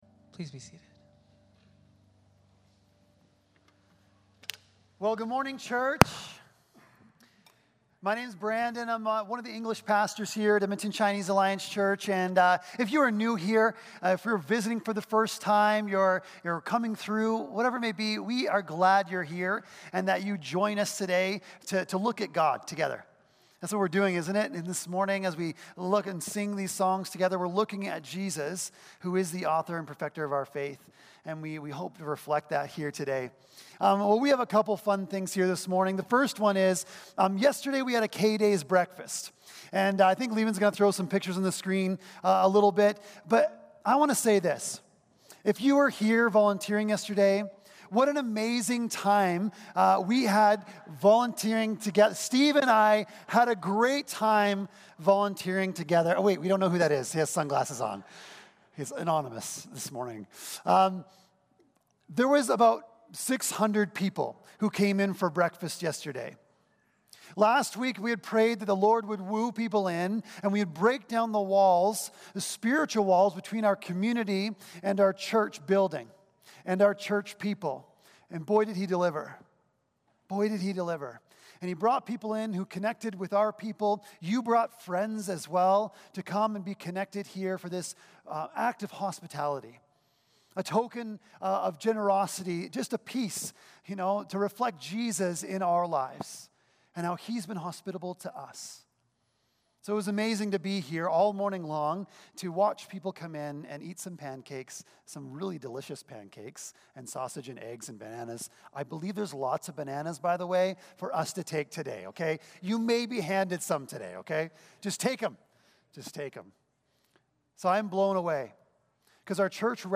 1-12 Service Type: Sunday Morning Service Passage